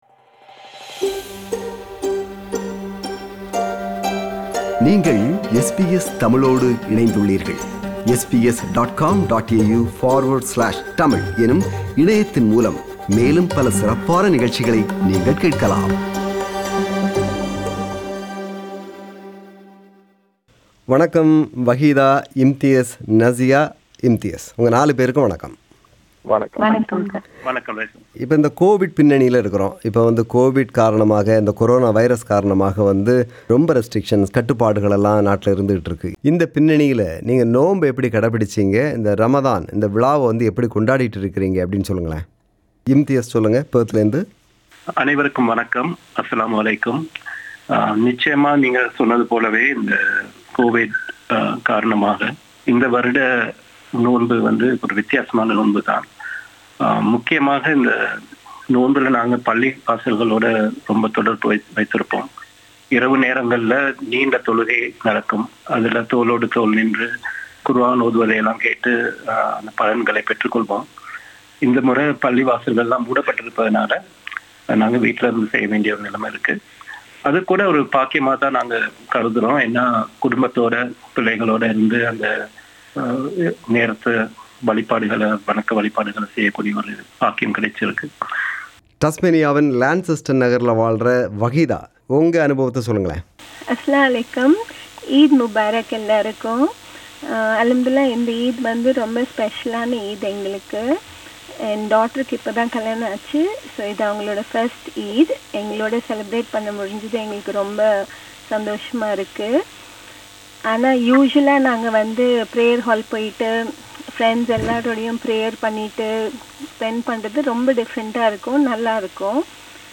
Ramadan special discussion